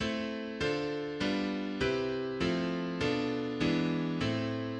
Adding sevenths to the chords creates a greater sense of forward momentum to the harmony:
Circle of fifths chord progression – minor with added sevenths